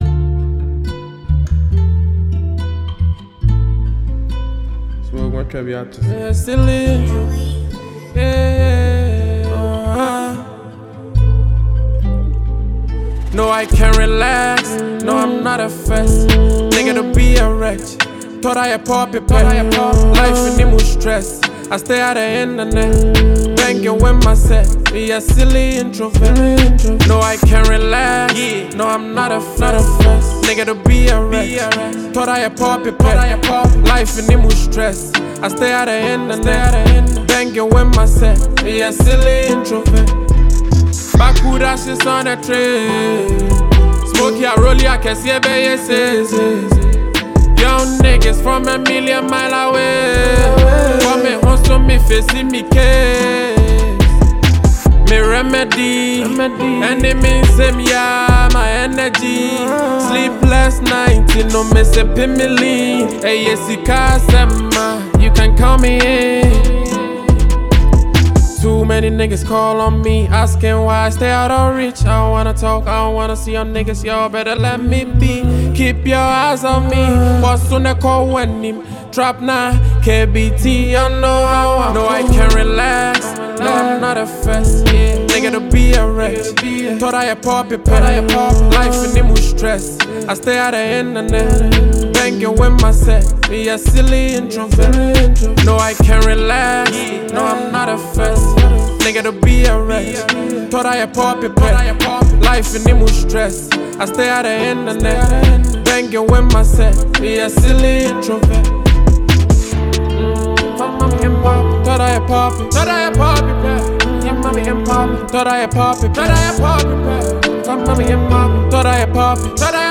• Genre: Afrobeat / Hip-Hop